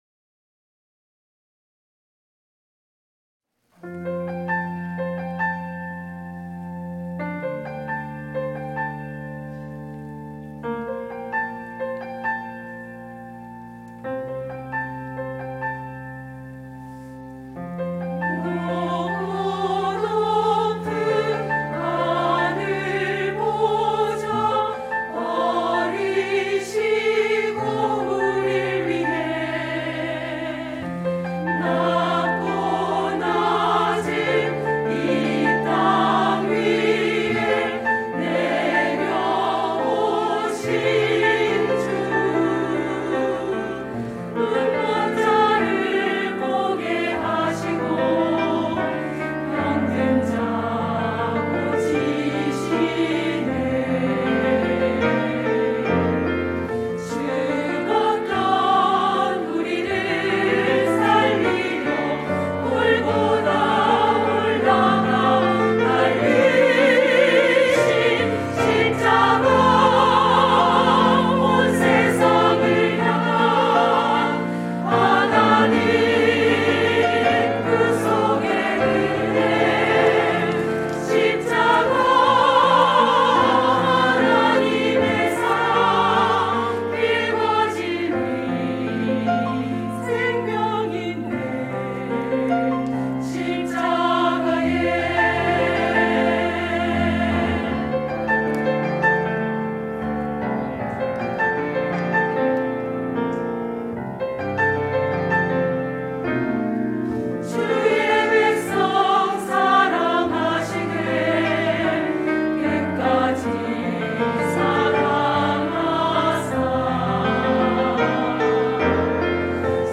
샤론